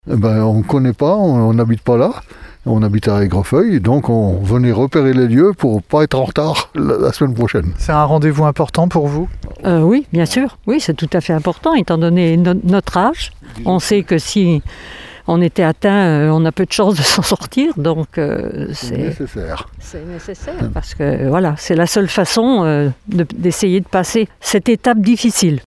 Réunion préparatoire hier après-midi au centre de vaccination de Surgères.